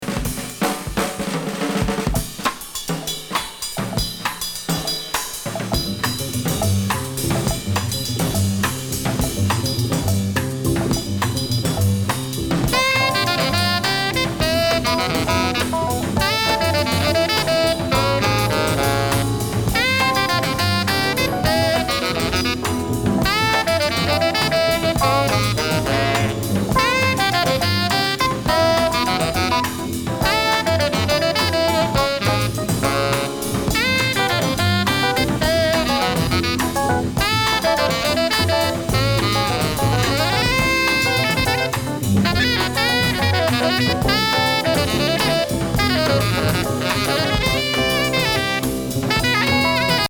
POST BOPな好内容。
PostBop,Brazilian,Crossover, ♪LISTEN LABEL/CAT.NUMBER